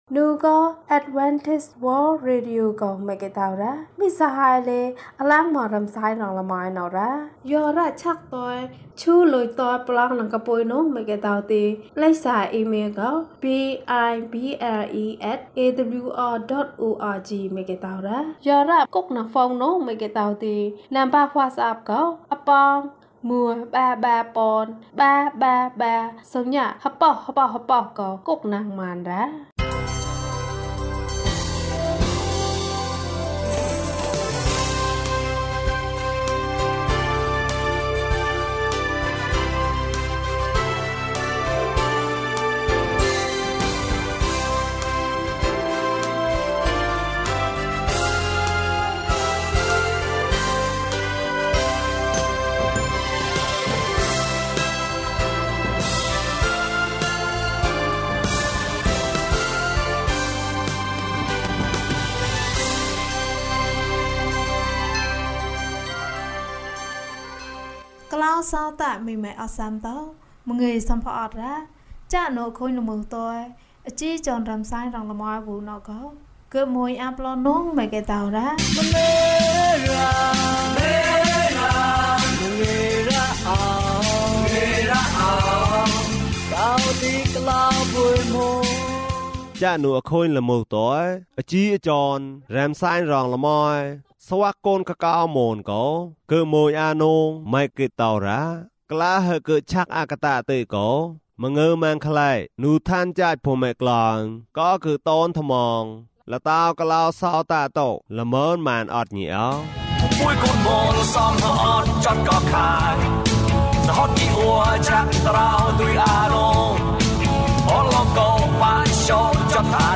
ခရစ်တော်ထံသို့ ခြေလှမ်း။၀၃ ကျန်းမာခြင်းအကြောင်းအရာ။ ဓမ္မသီချင်း။ တရားဒေသနာ။